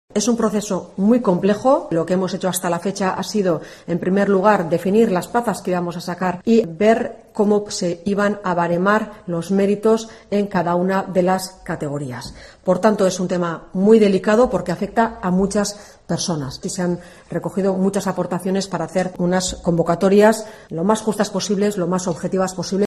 Eider Mendoza, portavoz de la Diputación de Gipuzkoa